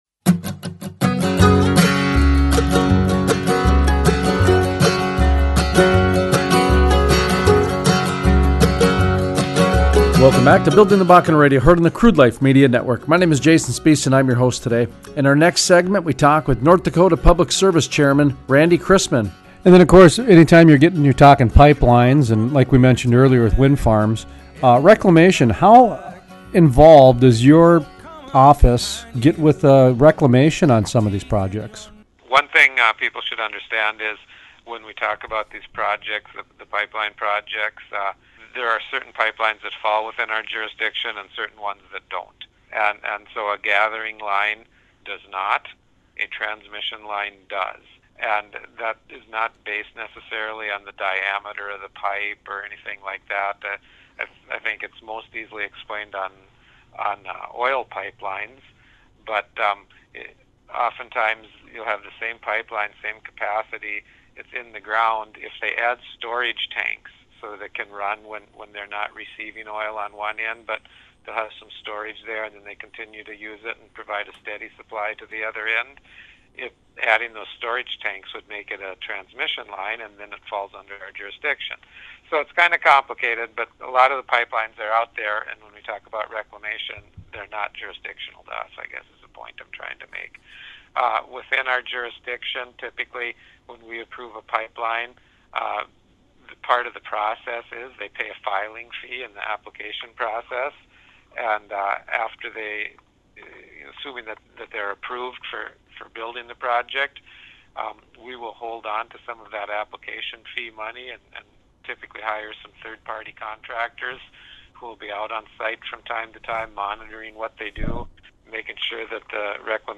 Interview: Randy Christmann, North Dakota Public Service Commissioner Chairman Christmann talks about reclamation with pipelines as well as wind farms. He also gives some anecdotal observations from his recent trips to the Bakken Oil Fields.